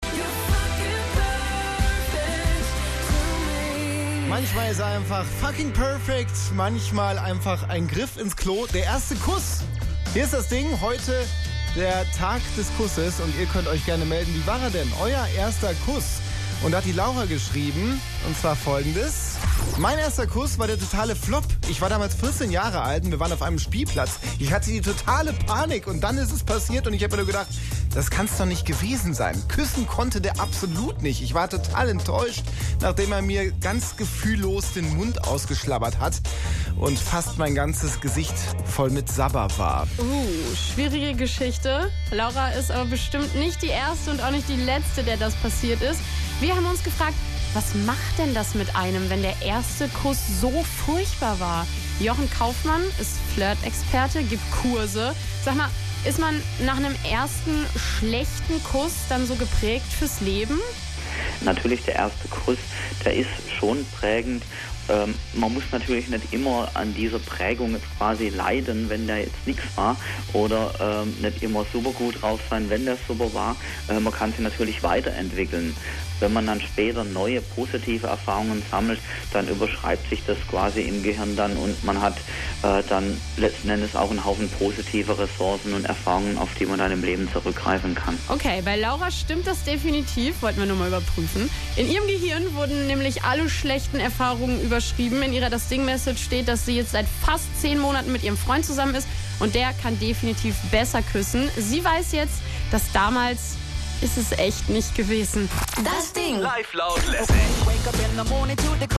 Radio-Beitrag des SWR übers Küssen
gesendet im Jugendsender des SWR DASDING am "Tag des Kusses"